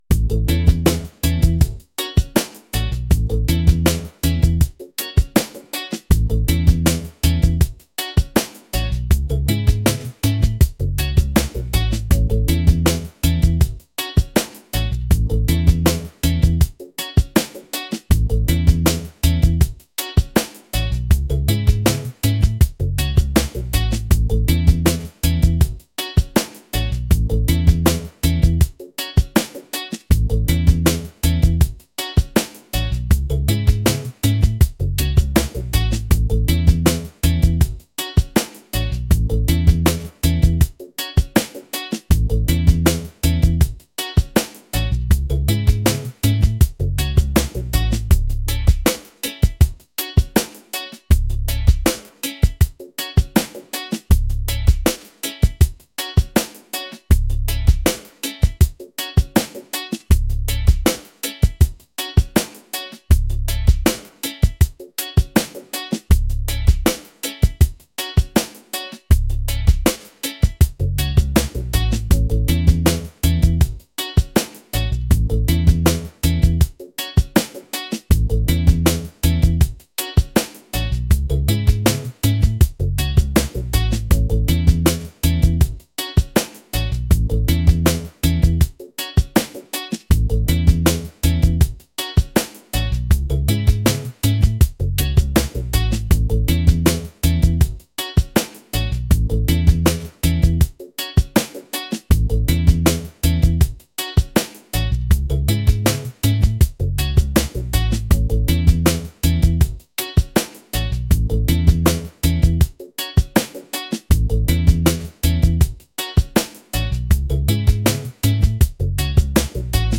laid-back | island | vibes | reggae